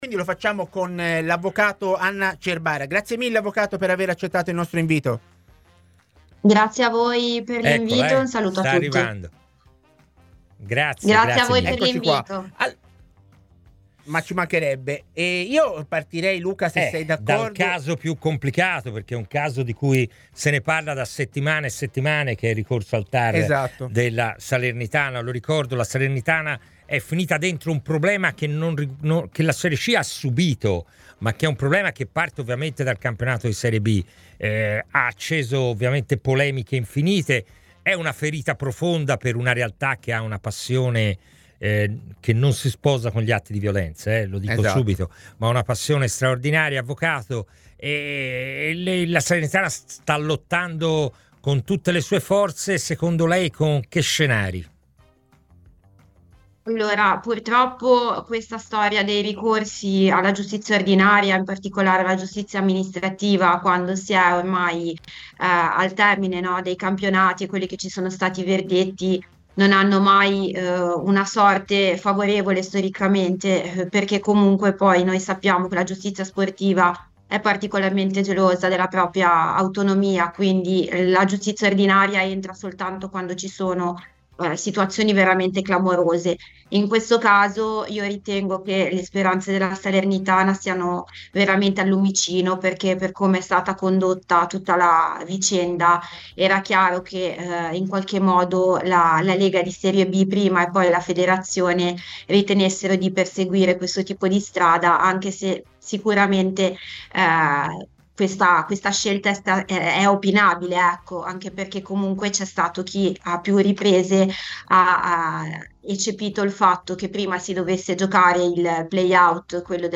è intervenuto l'avvocato